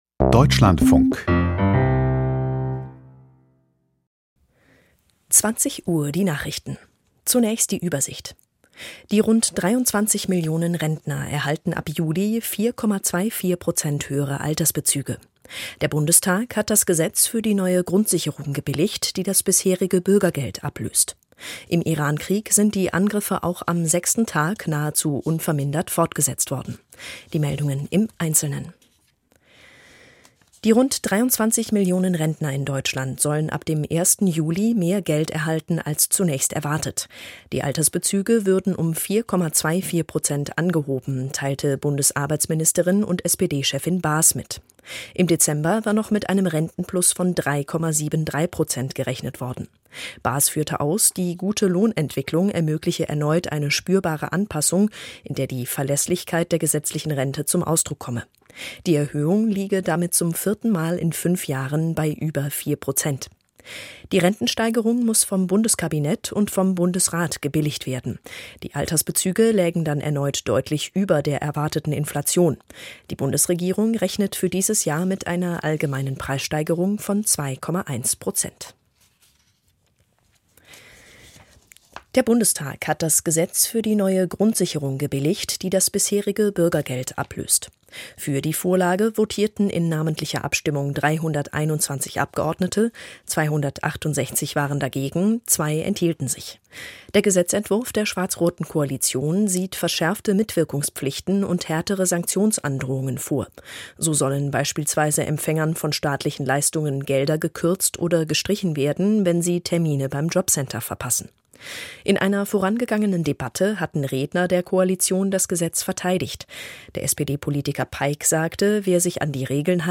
Die Nachrichten vom 05.03.2026, 20:00 Uhr